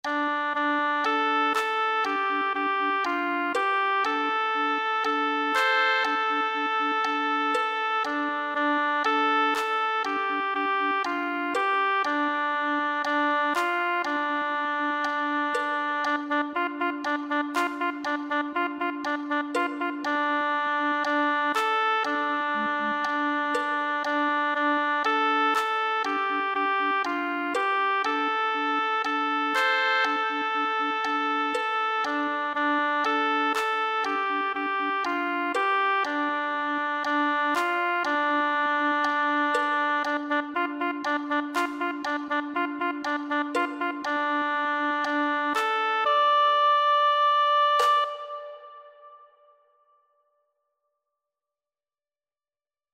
“Melodía con aire árabe para cantar- tocar y bailar„
1ª Voz